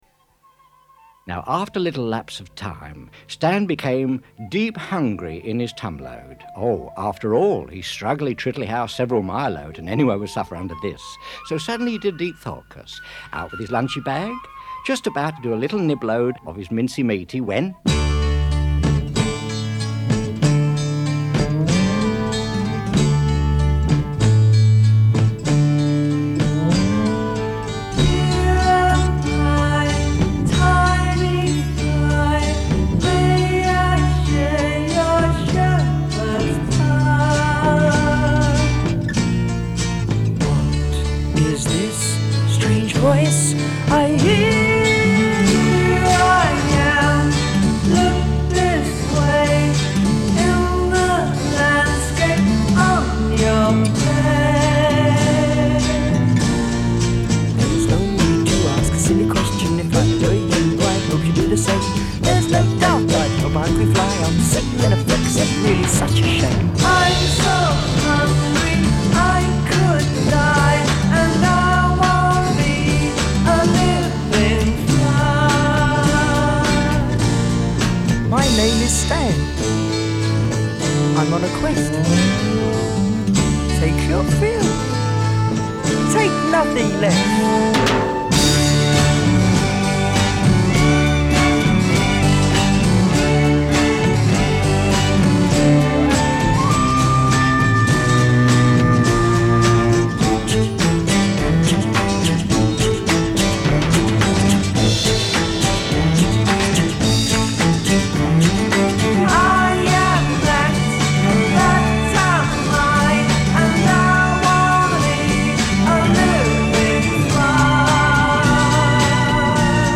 Жанр: Psychedelic Rock